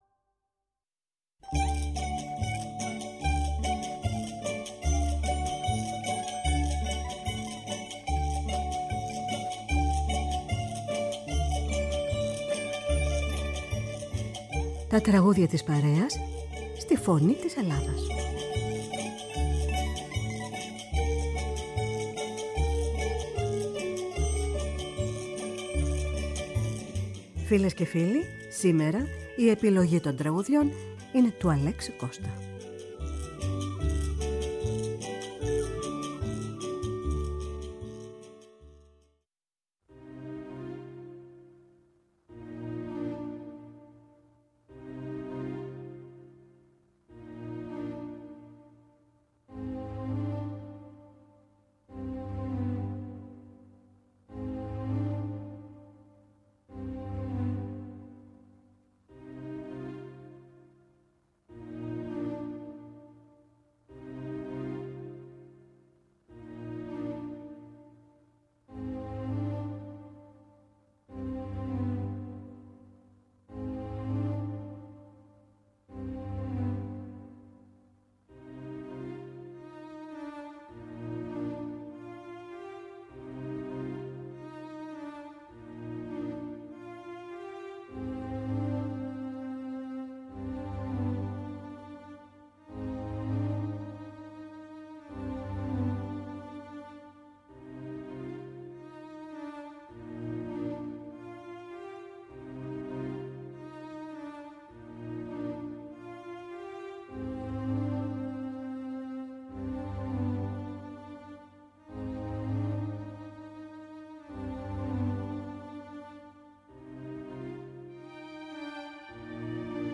Με μουσικές από την Ελλάδα και τον κόσμο. Η ΦΩΝΗ ΤΗΣ ΕΛΛΑΔΑΣ Τα Τραγουδια της Παρεας στη Φωνη της Ελλαδας Μουσική